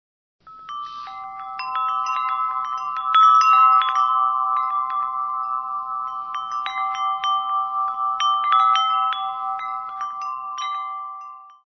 Woodstock Chimes, Bells and Gongs